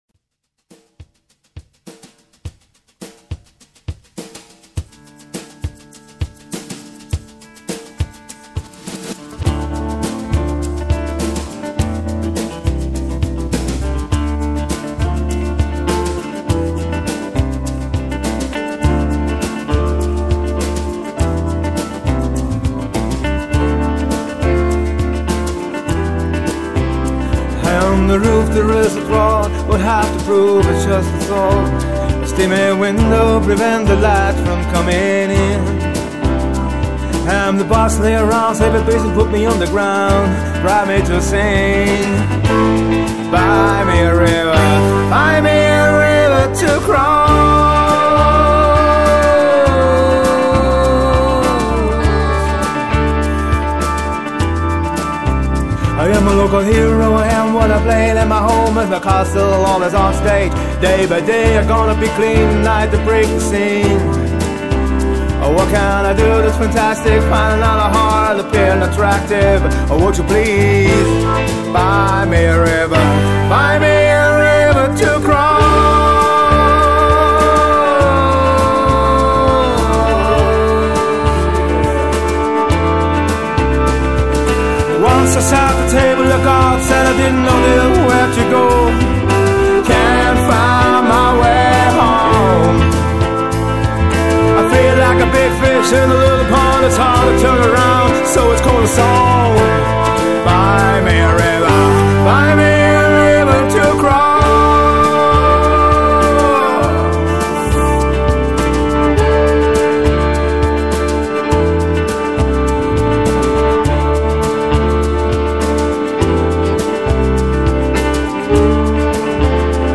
keyboards & violine